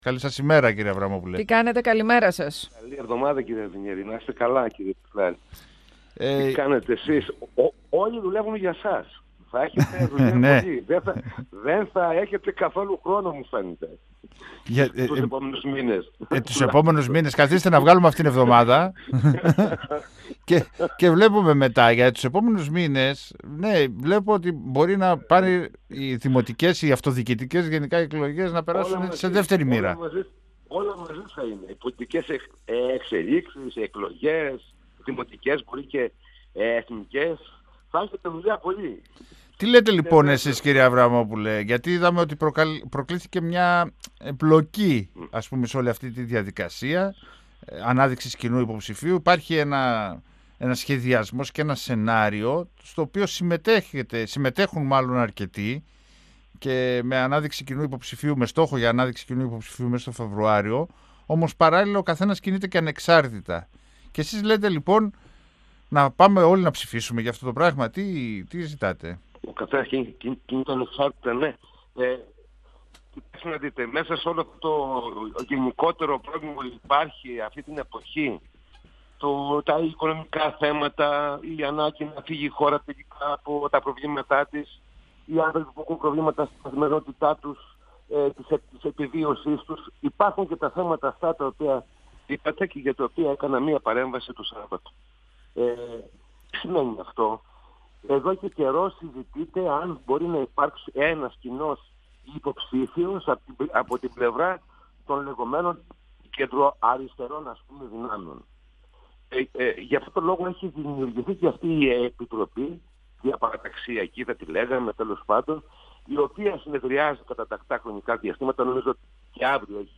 Ανοικτές προκριματικές εκλογές για να αναδειχθεί ο υποψήφιος της Κεντροαριστεράς για το δήμο Θεσσαλονίκης προτείνει ο ανεξάρτητος δημοτικός σύμβουλος και πρώην πρόεδρος του δημοτικού συμβουλίου Παναγιώτης Αβραμόπουλος. Μιλώντας στον 102FM του Ραδιοφωνικού Σταθμού Μακεδονίας της ΕΡΤ3, ο κ. Αβραμόπουλος κάλεσε το ΣΥΡΙΖΑ να συμμετάσχει στη διαδικασία και εκτίμησε ότι αν υπάρχει μεγάλος αριθμός υποψηφίων «θα χάσουμε όλοι».
Συνεντεύξεις